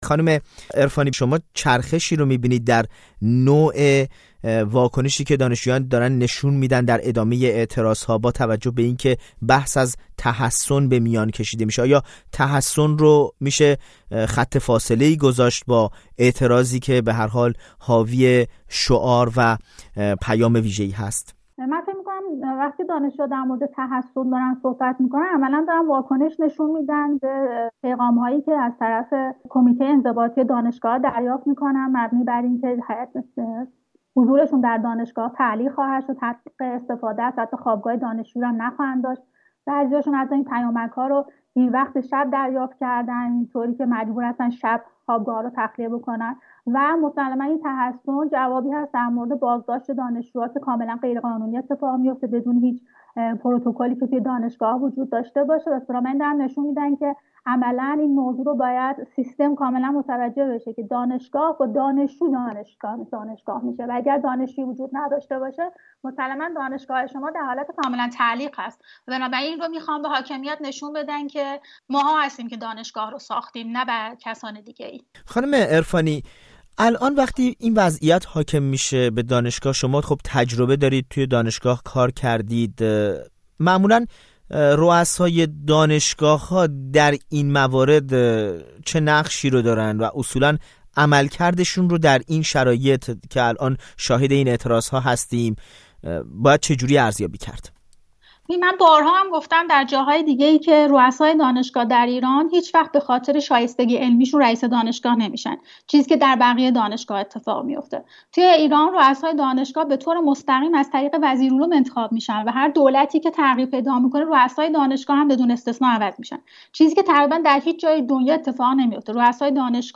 گفت و گو کرده است.